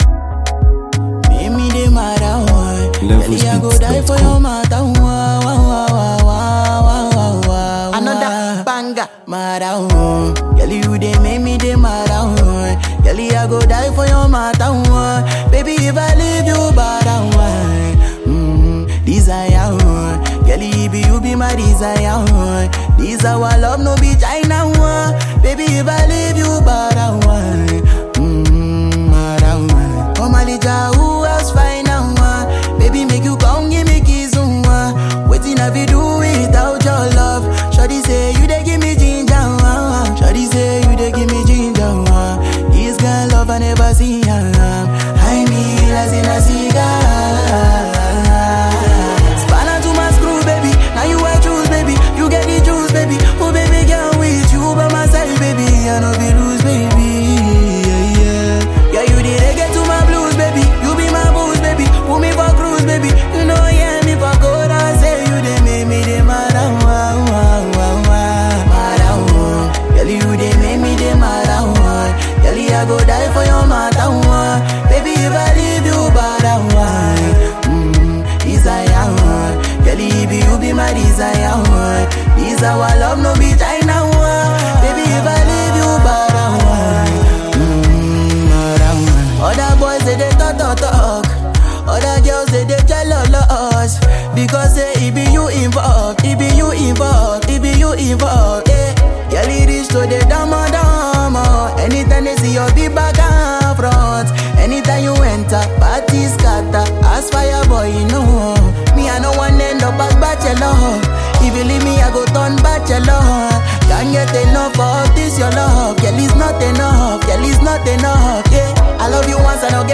confident and energetic anthem